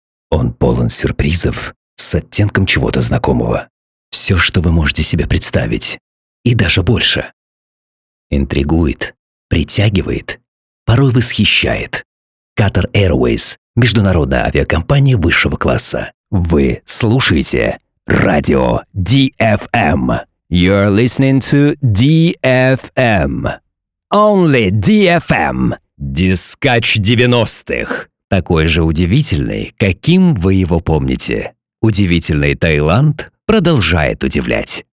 Masculino
Dry voice
Rich, warm, cool, young, adult